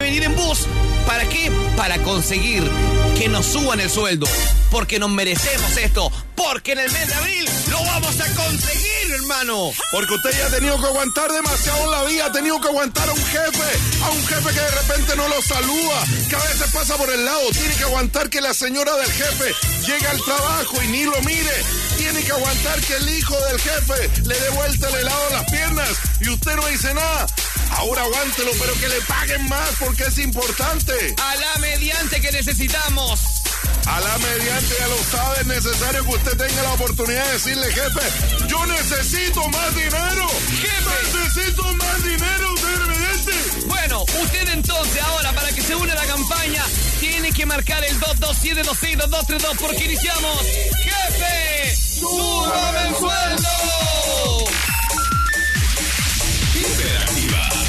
Escucha el audio de la campaña y el llamado de la radioescucha para que te motives, en pedir no hay engaño.